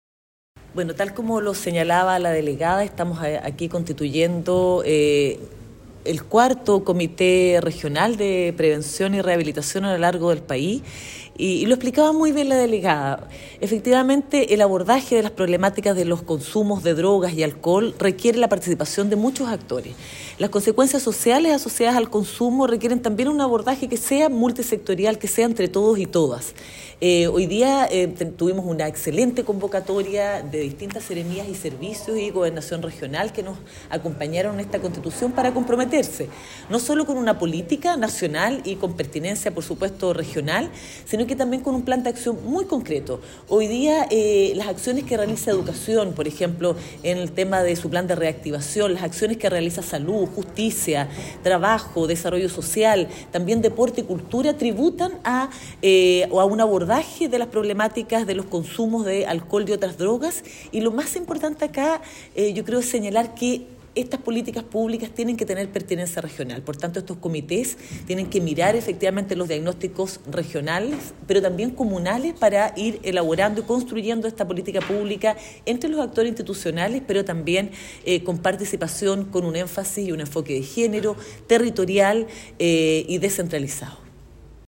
Cuña-Directora-Nacional-de-SENDA-Natalia-Riffo.mp3